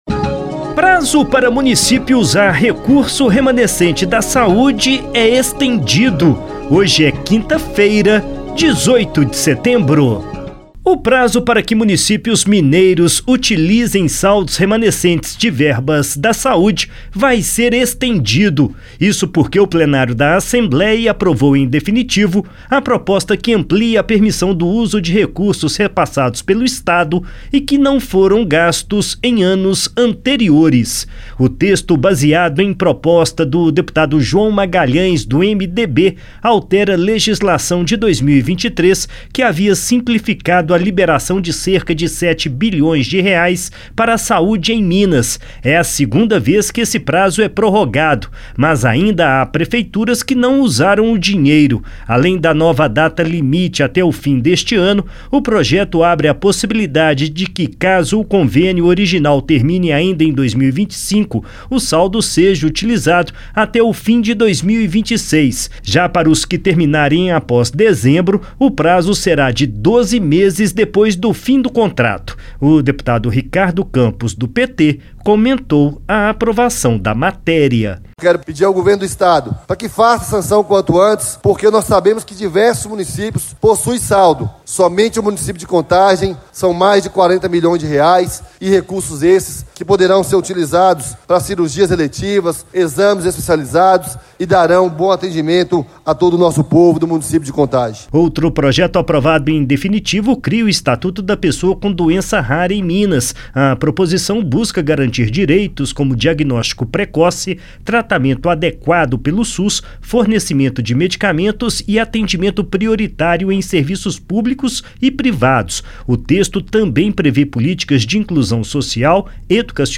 Boletim da ALMG - Edição n.º 6255